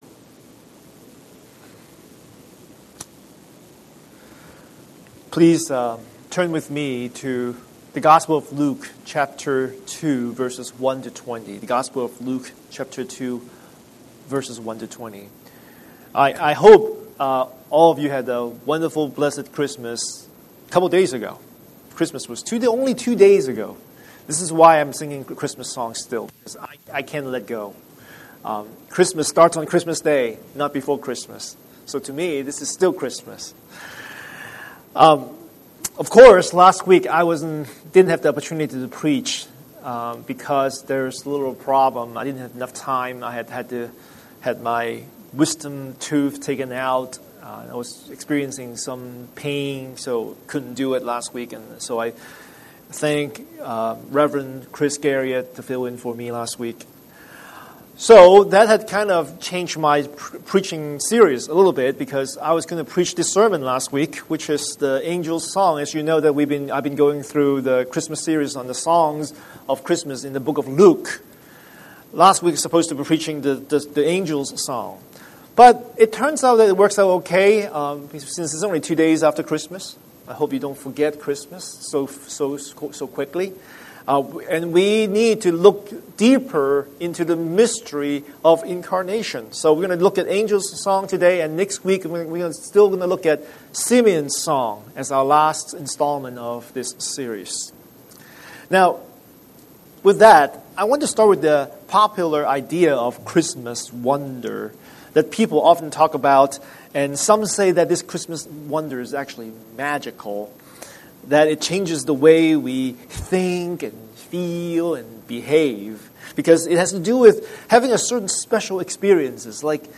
Scripture: Luke 2:1-20 Series: Sunday Sermon